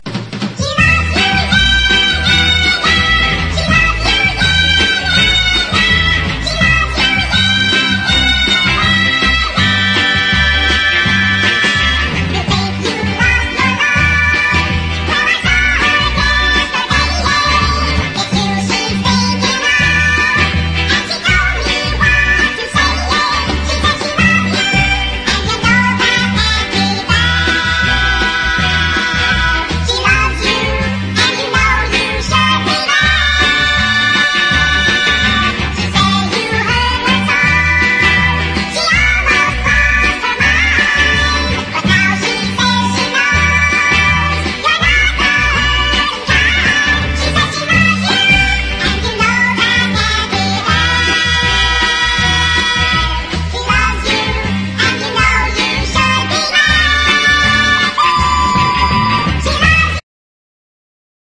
全16曲、もちろん全部ムシ声。。。。